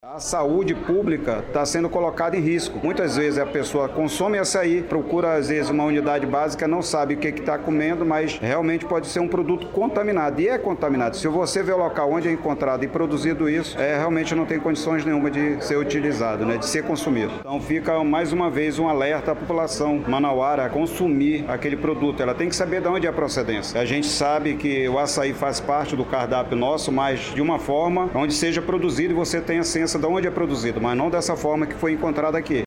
SONORA-2-INTERDICAO-COMERCIO-ACAI-.mp3